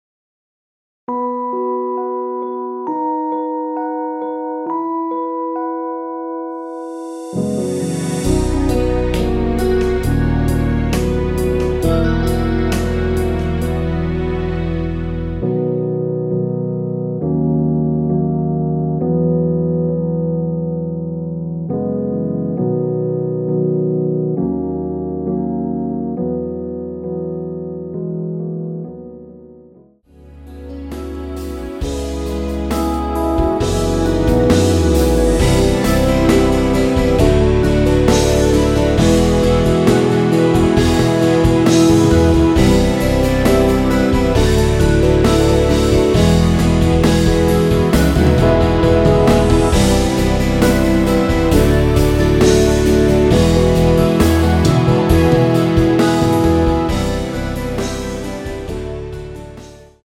원키에서(-3)내린 MR입니다.
앞부분30초, 뒷부분30초씩 편집해서 올려 드리고 있습니다.
중간에 음이 끈어지고 다시 나오는 이유는